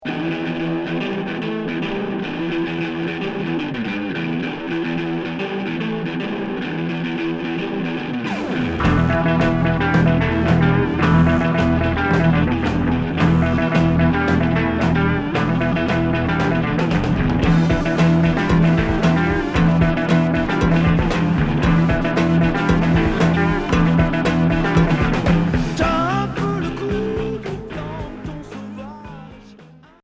Heavy rock progressif